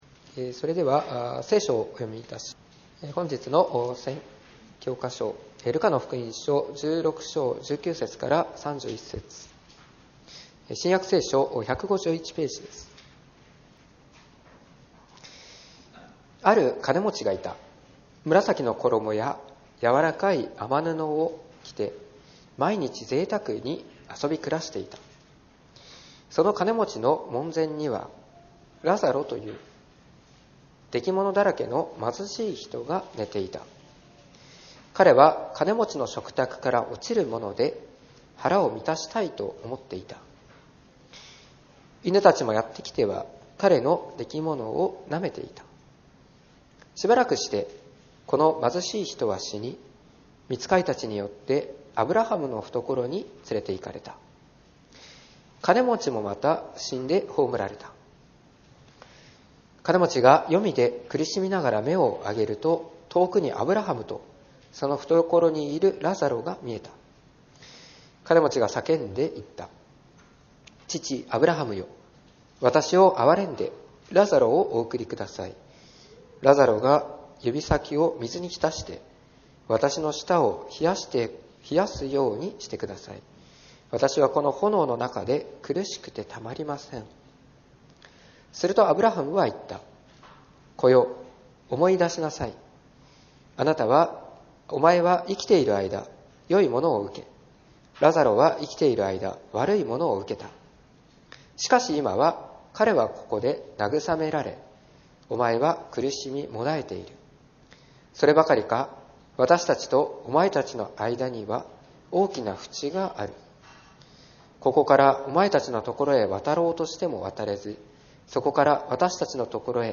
2025年2月23日礼拝説教「悔い改めるためには」